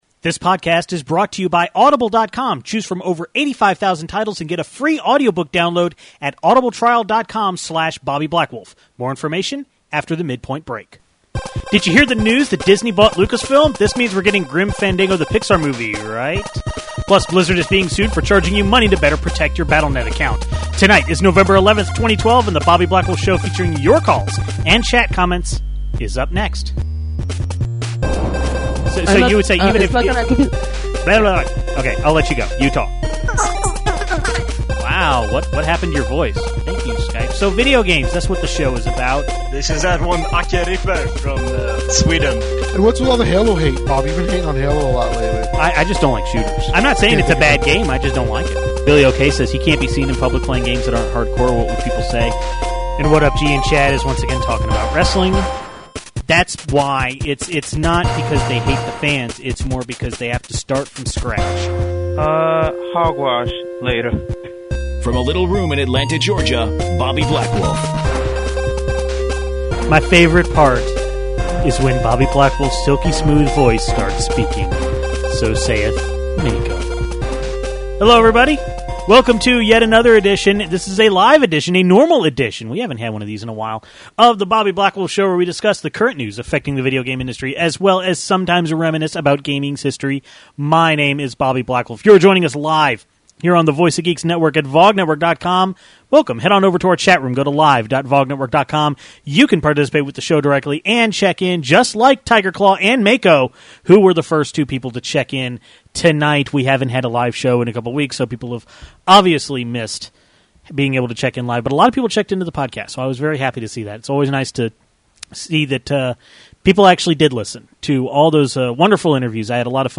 Then we take calls about Halo 4 and Wreck-It Ralph.